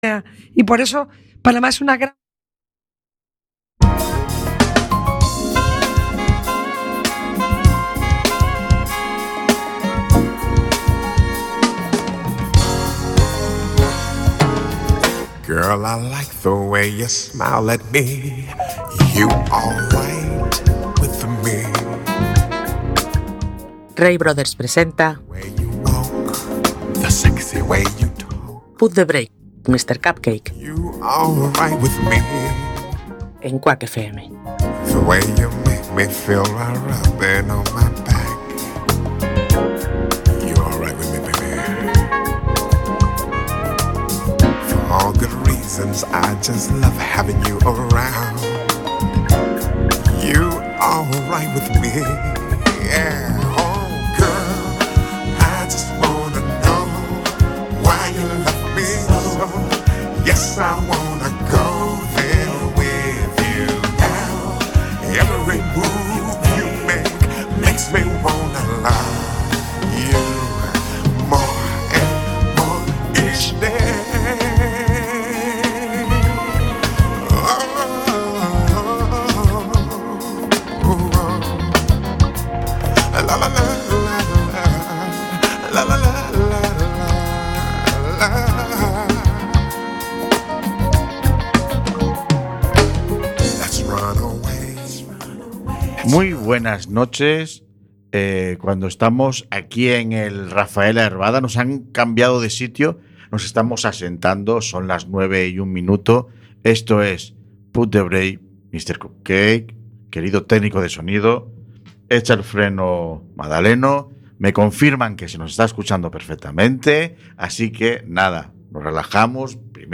En esta ocasión, abrimos el micrófono a las compañeras de Fibrovisibles, una asociación de A Coruña que se ha convertido en un pilar fundamental de apoyo y comprensión para quienes viven con fibromialgia y otras enfermedades invisibles.